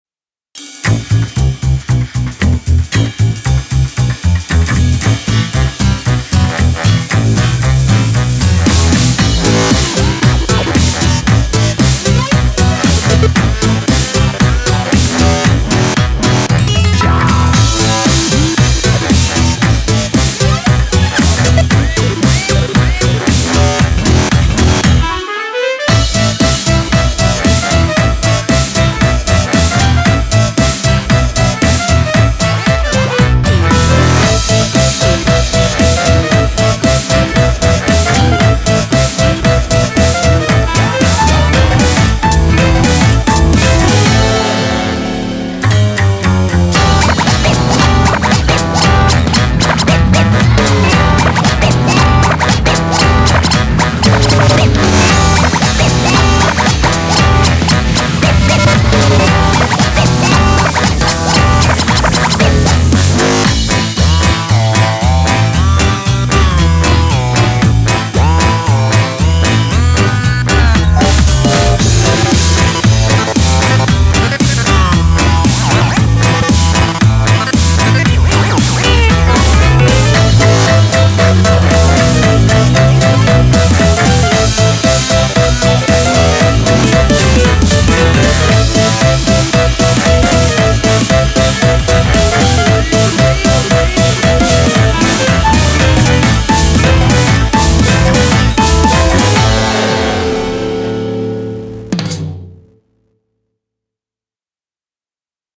BPM115
Audio QualityPerfect (High Quality)